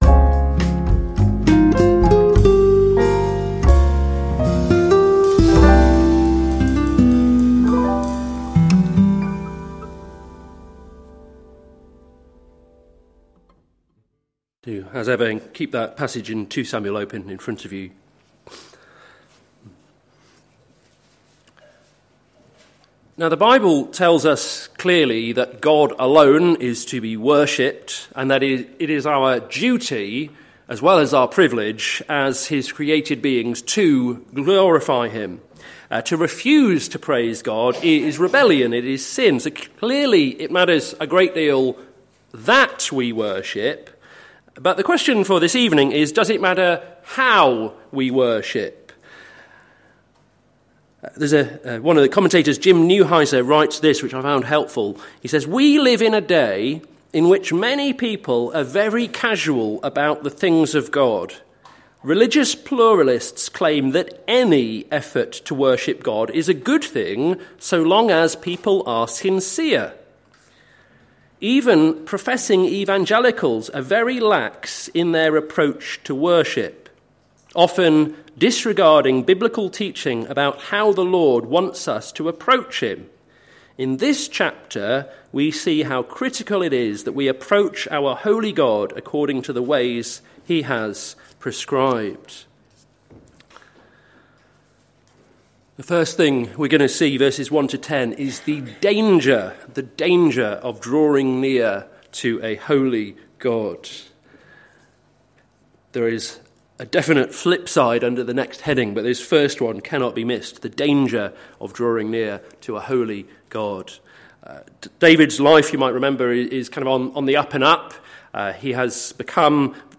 Sermon Series - May the Lord establish His Word - plfc (Pound Lane Free Church, Isleham, Cambridgeshire)